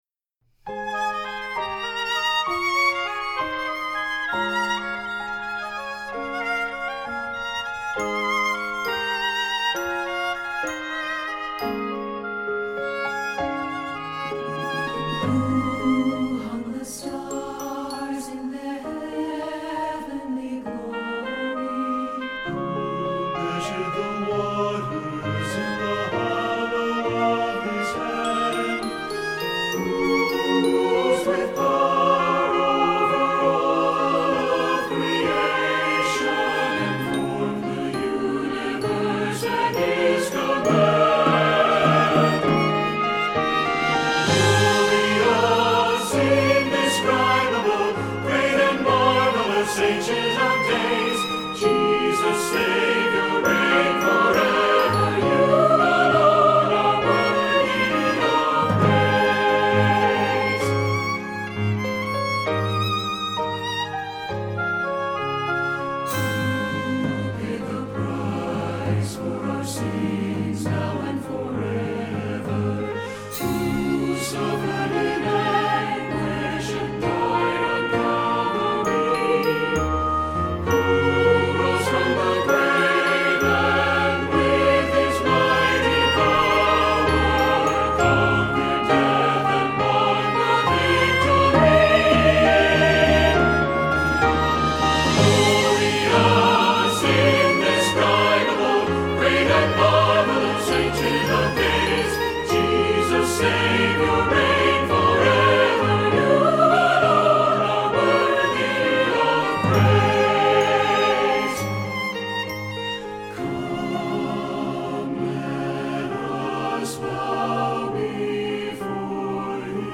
Voicing: SATB